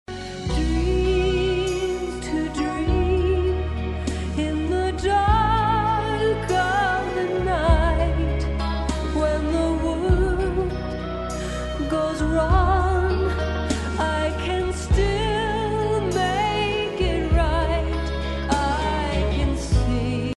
Tukaj sem naredil par fileov za testiranje kvalitete mojega kodeka, mp3-ja in original wave-a (CDja).